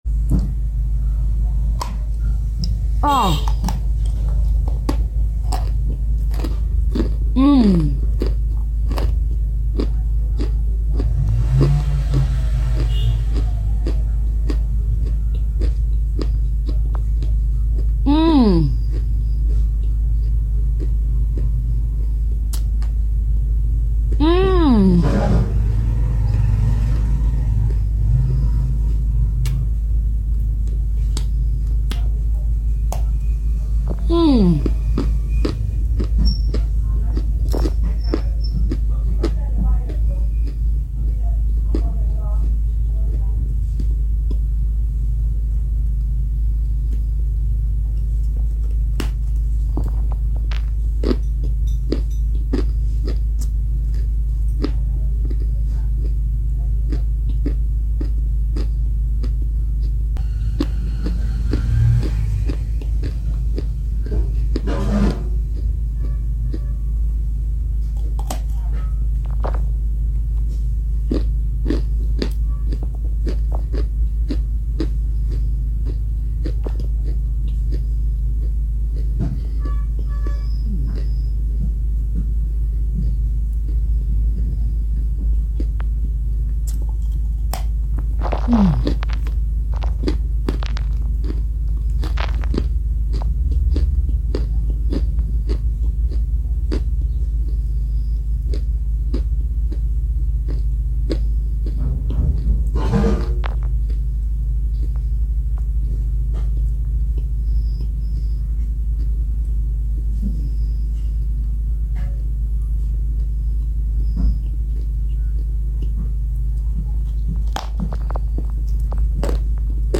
degustation kaolin ASMR ( ne sound effects free download